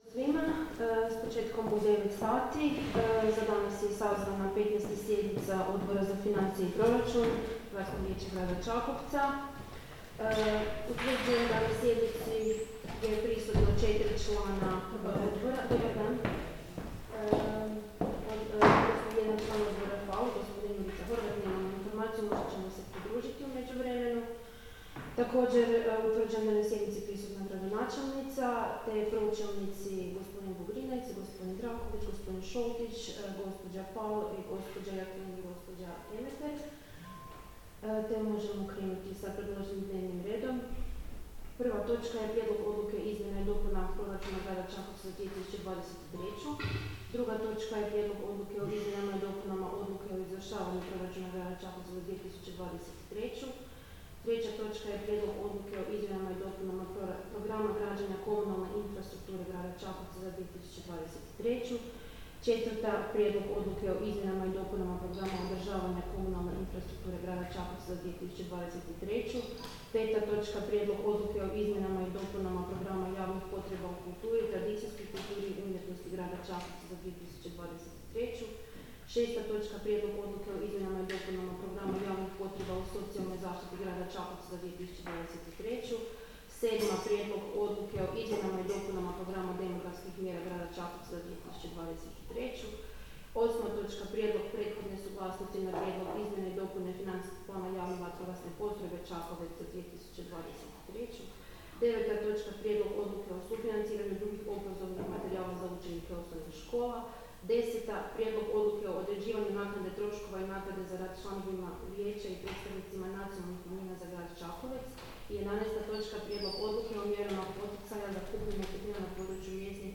Obavještavam Vas da će se 15. sjednica Odbora za financije i proračun Gradskog vijeća Grada Čakovca održati dana 12. lipnja 2023. (ponedjeljak), u 09.00 sati, u gradskoj vijećnici Grada Čakovca.